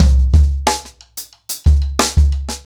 Expositioning-90BPM.15.wav